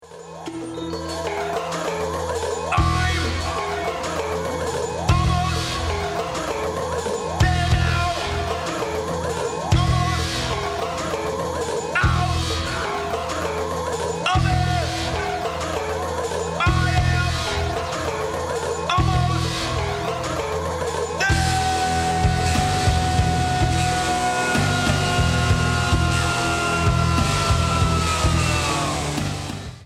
percussion, deep breathing,
rhythm programming, vocals, mayhem
keyboards & effects
guitar
bass